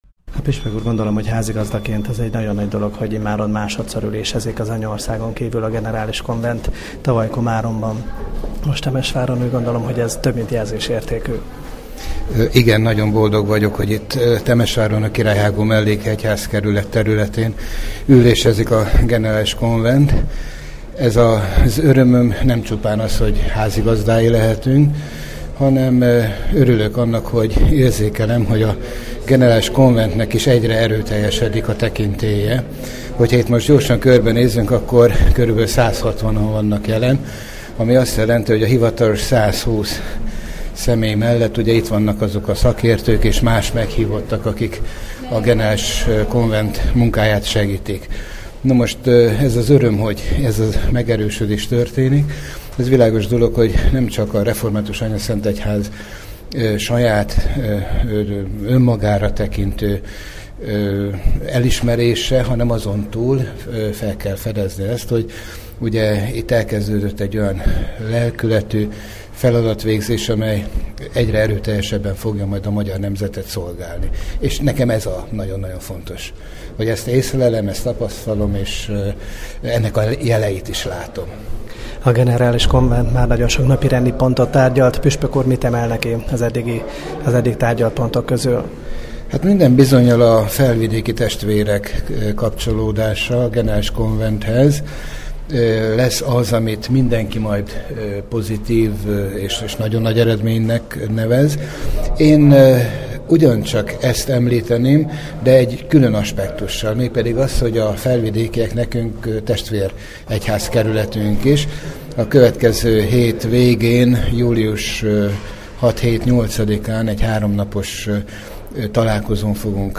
Fényképeket és rádiós riportokat nézhetnek meg illetve hallgathatnak meg a Generális Konvent június 21-22-ei plenáris üléséről.
Csűry István, a házigazda Királyhágómelléki Református Egyházkerület püspöke. A vele készült interjút itt hallgathatják meg.